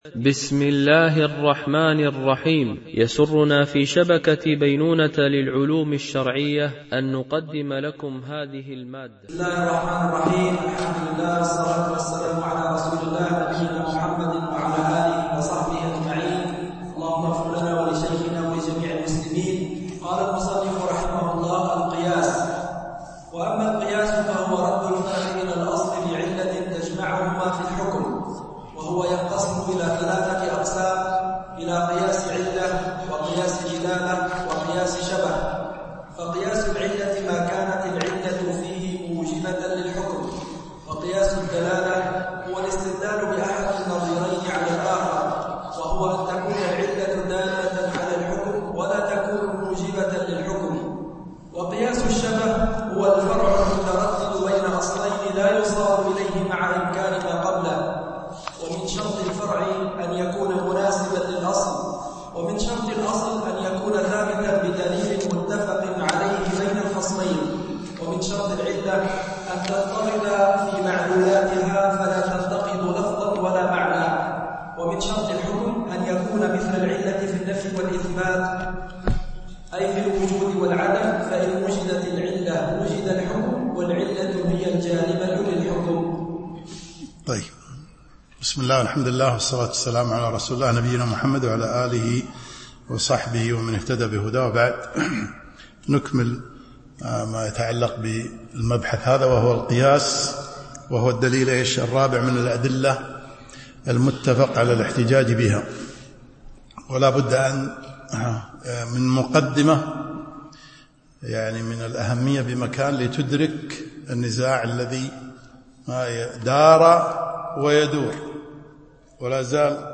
دورة علمية في شرح الورقات في أصول الفقه للجويني
MP3 Mono 22kHz 32Kbps (CBR)